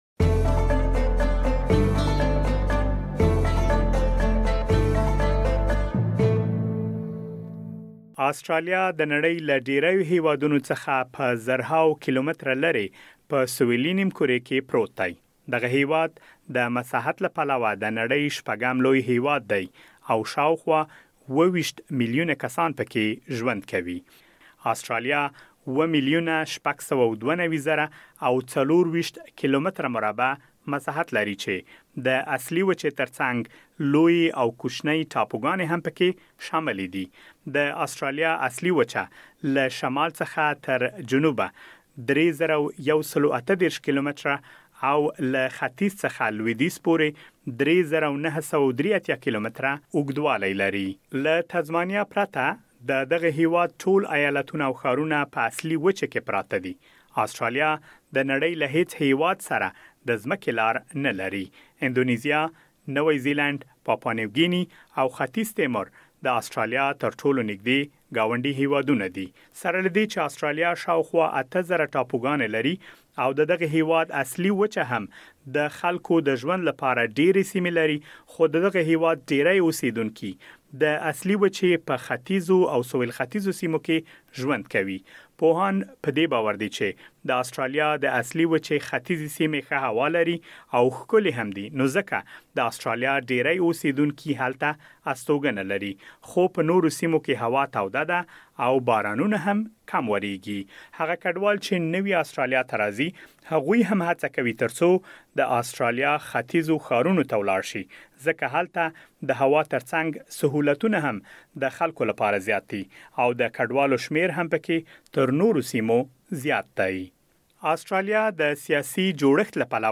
د آسټرالیا د جغرافیې په اړه مهم معلومات په رپوټ کې اورېدلی شئ.